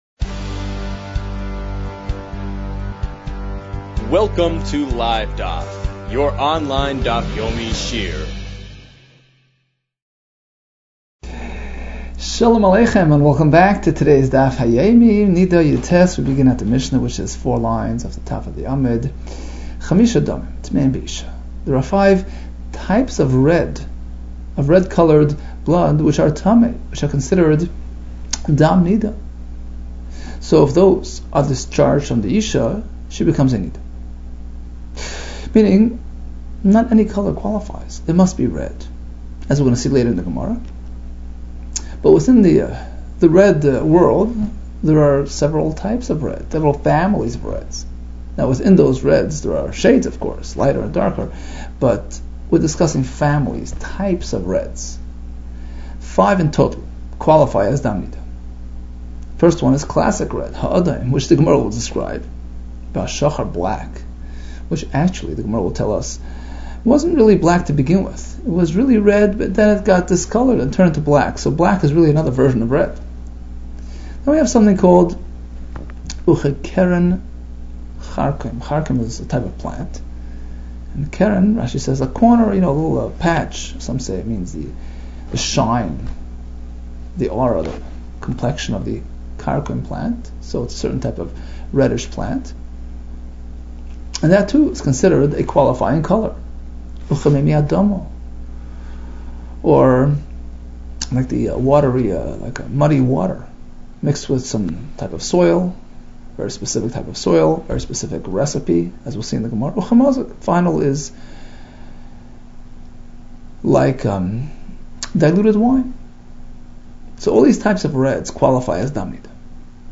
Niddah 19 - נדה יט | Daf Yomi Online Shiur | Livedaf